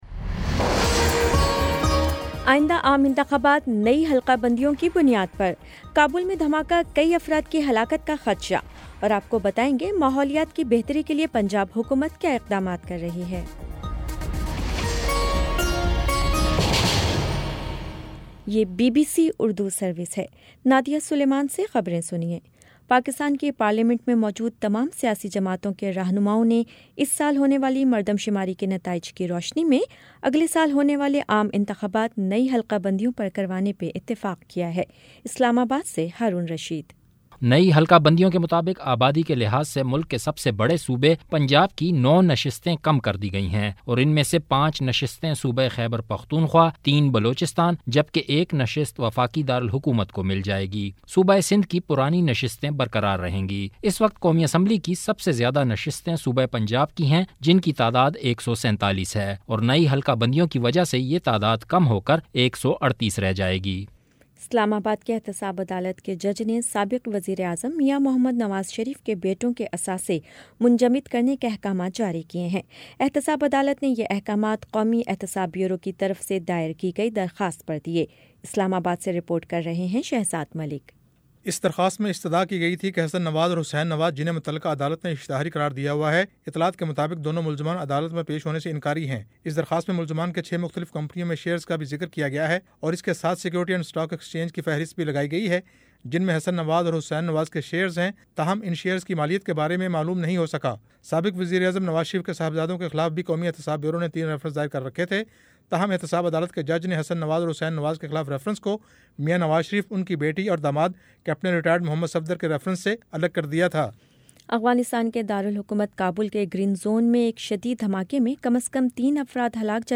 اکتوبر 31 : شام سات بجے کا نیوز بُلیٹن